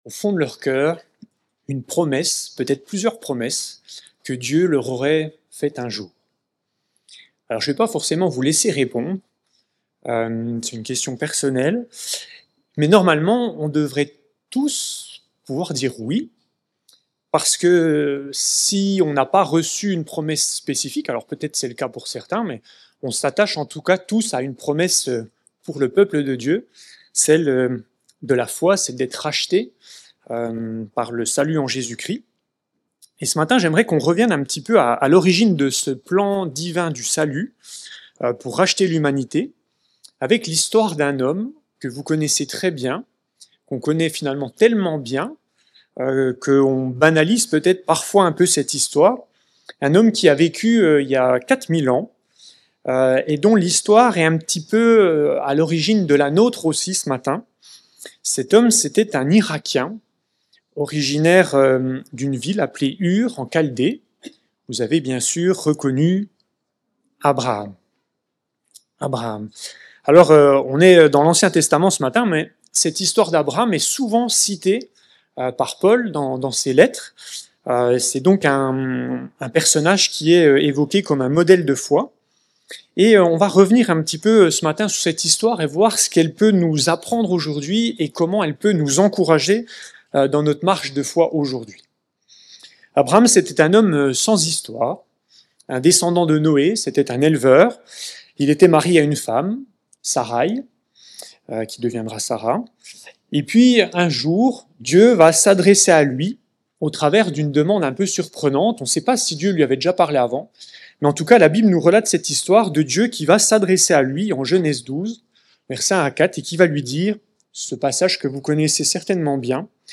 SUMMARY:Culte hebdomadaire